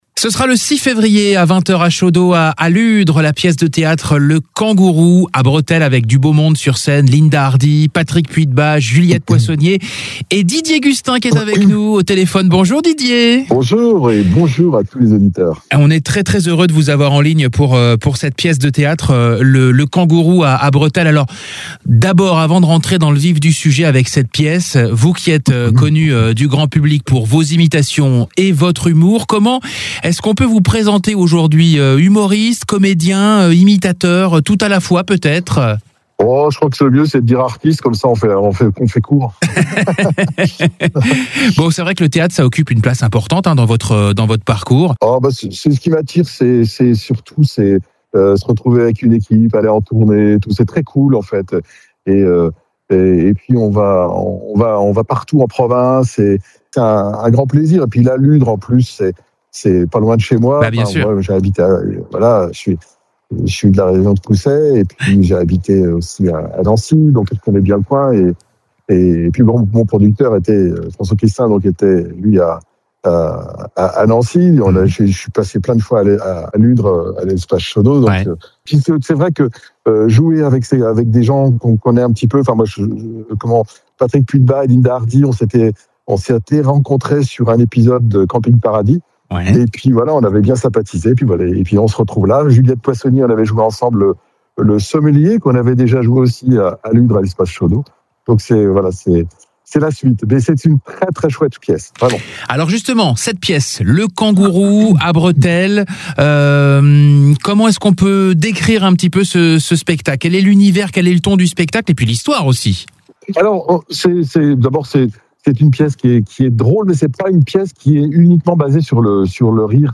INTERVIEW INTEGRALE